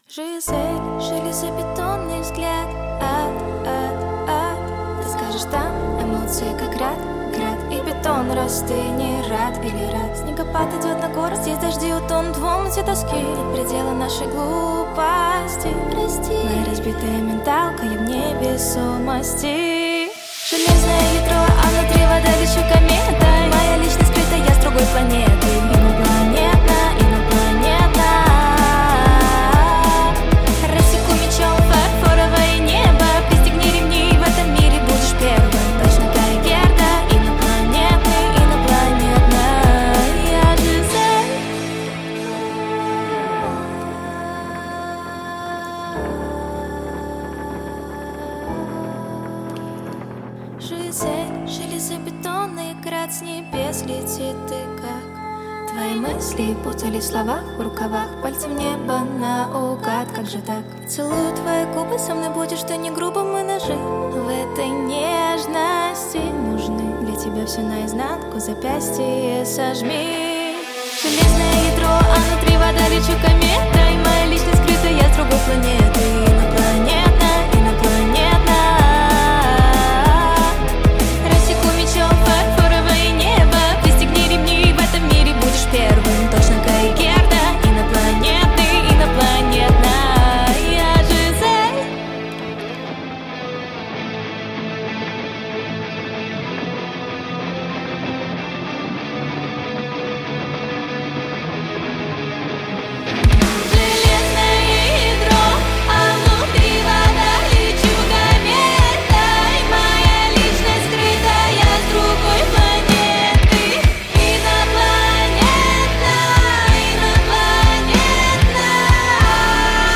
Любители live-show могут посетить прямой эфир лично – транслировать мы будем из самого центра Москвы – из бара GOOS’ TO WINE, винный бар!